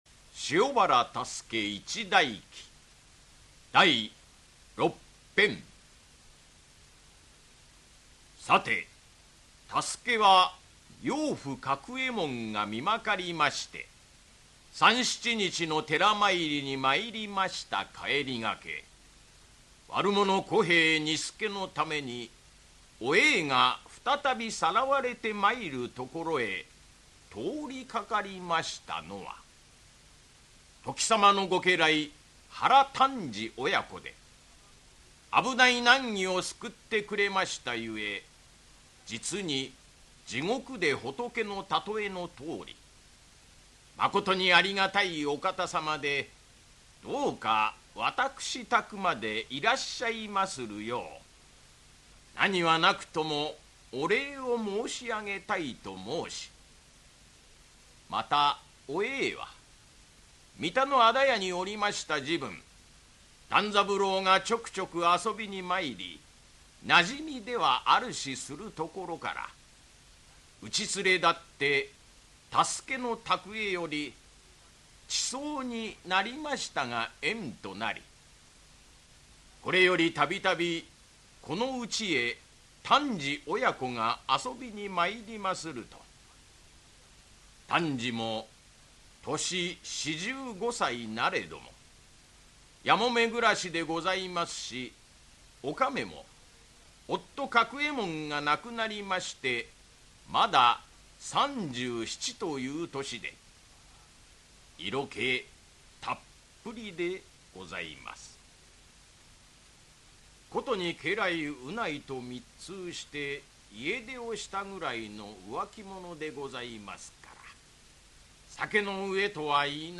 [オーディオブック] 塩原多助一代記-第六・七編-